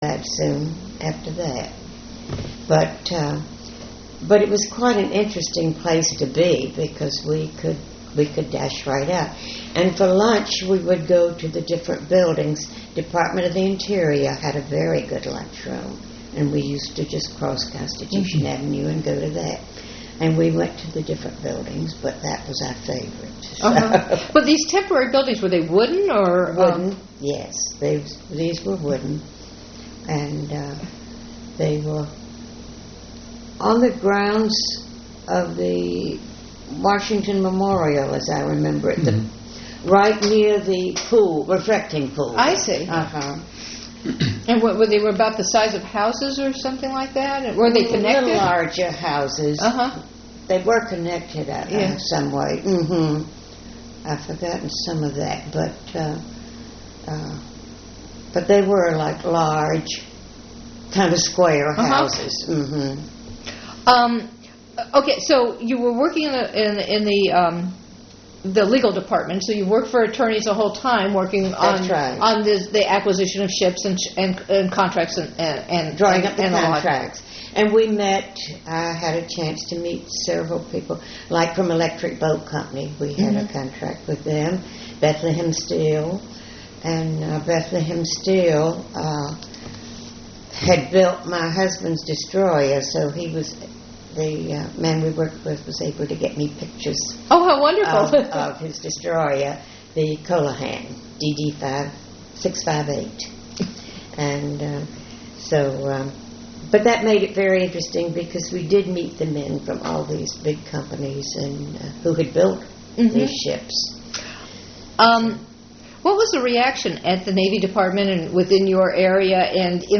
Oral History Recording
Interview place Interviewee's home